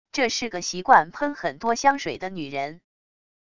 这是个习惯喷很多香水的女人wav音频生成系统WAV Audio Player